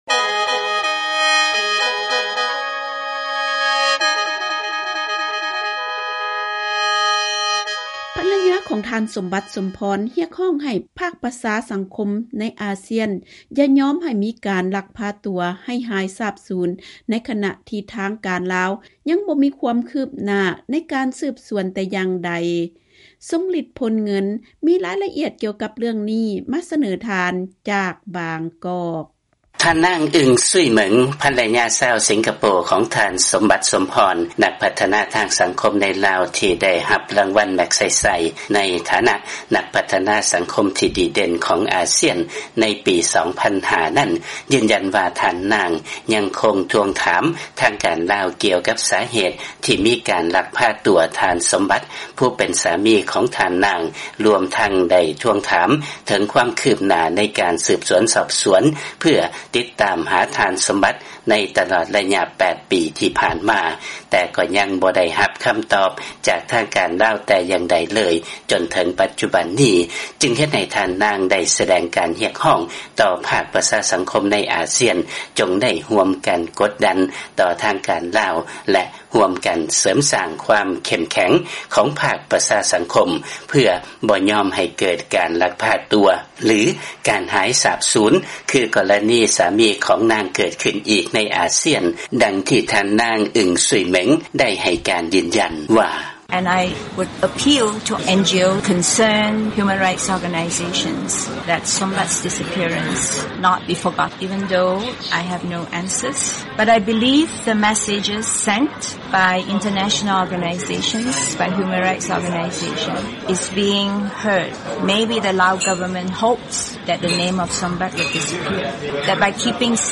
ຟັງລາຍງານ ພັນລະຍາຂອງ ທ. ສົມບັດ ສົມພອນ ຮຽກຮ້ອງໃຫ້ພາກ ອົງການປະຊາສັງຄົມ ໃນອາຊຽນ ແລະອົງການສາກົນ ຢ່າຍອມໃຫ້ມີການລັກພາຕົວໄປ ເກີດຂຶ້ນອີກ